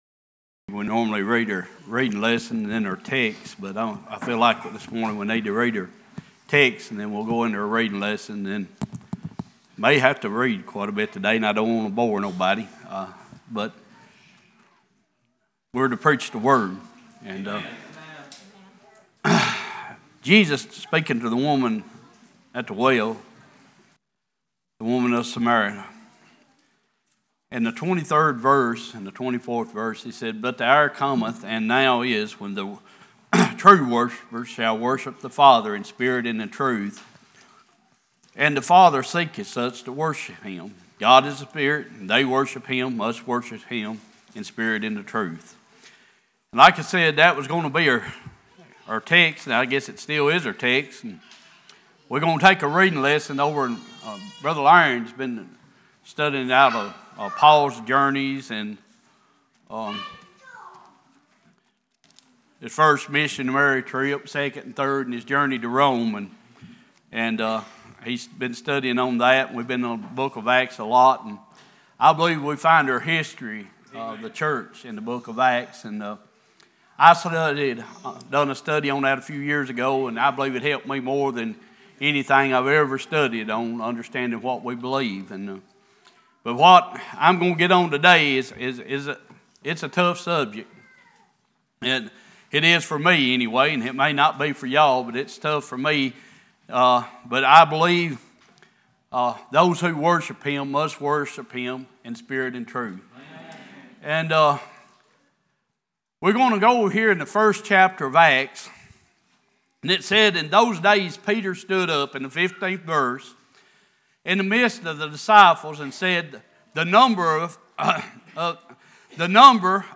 Sunday Morning Passage: John 4:23-24, Acts 1:15-26, Acts 2:36-47, Acts 18:24-28, Acts 19:1-6 Service Type: Worship « He Is the Resurrection Macedonian Call